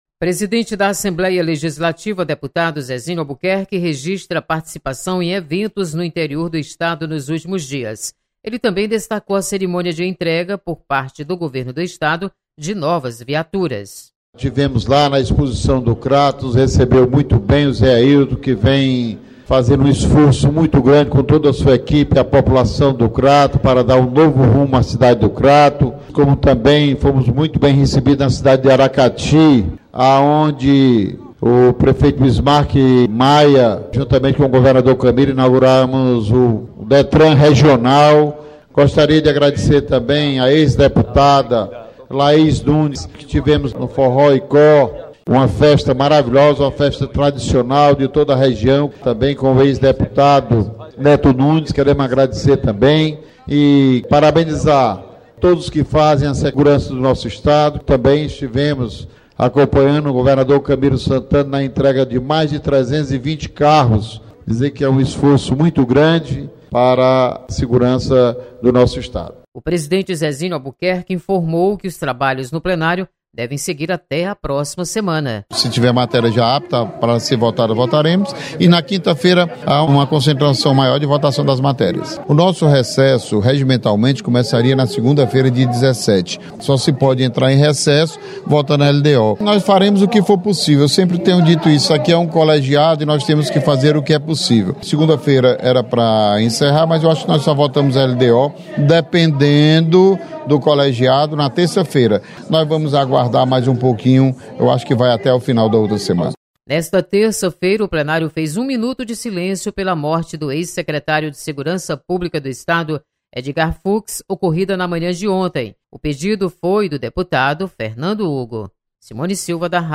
Deputado Zezinho Albuquerque Repórter destaca investimento em novas viaturas.